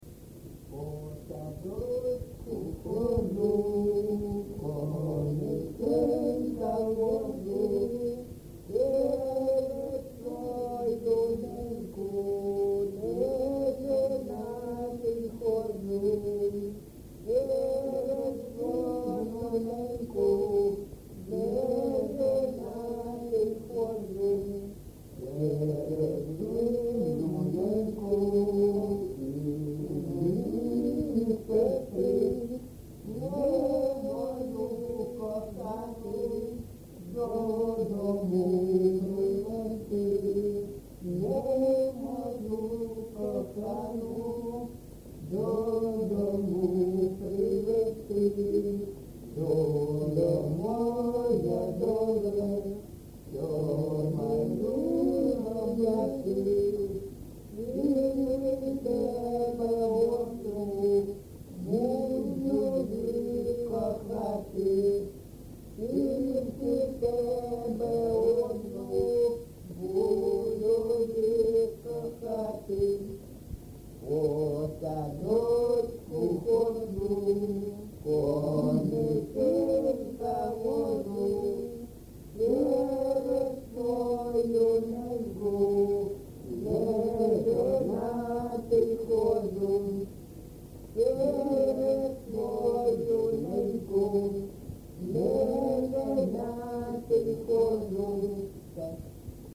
ЖанрПісні з особистого та родинного життя
Місце записум. Часів Яр, Артемівський (Бахмутський) район, Донецька обл., Україна, Слобожанщина